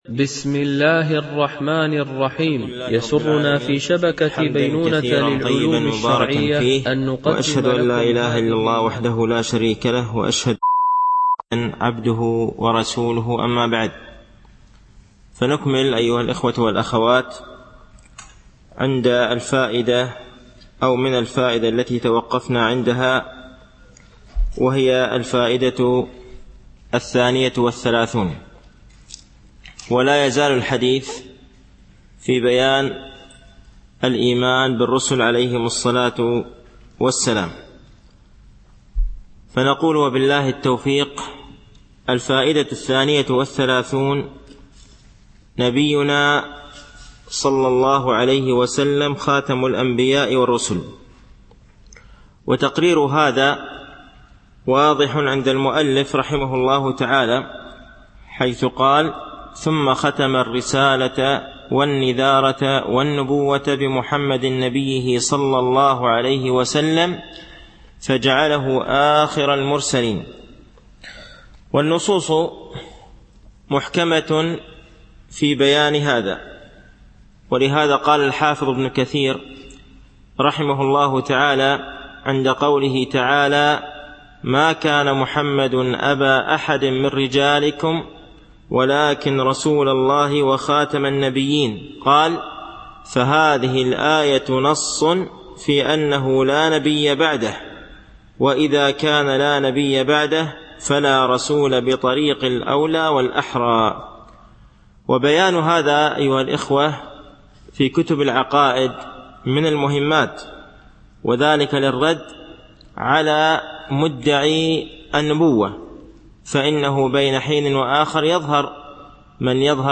القواعد والفوائد المستفادة من مقدمة ابن أبي زيد القيرواني المالكي ـ الدرس الرابع
Mono